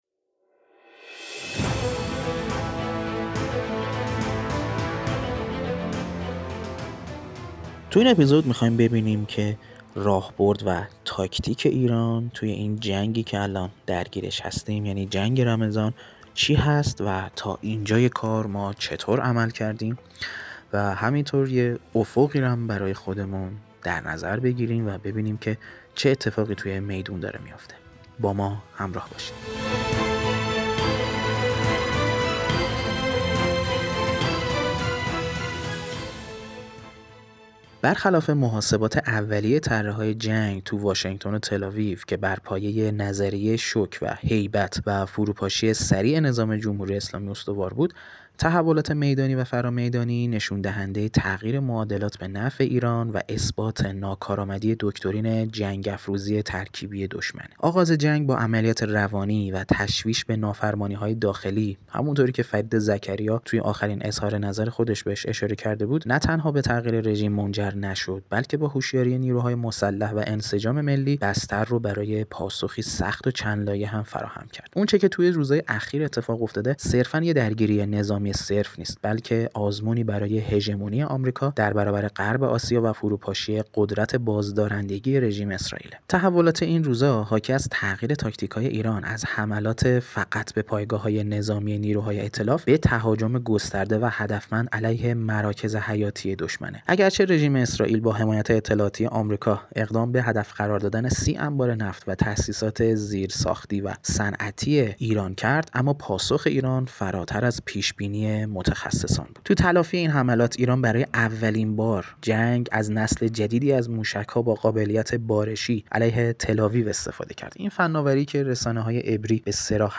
آناکست؛ مستند